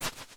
Whoosh & Slash
Launch.wav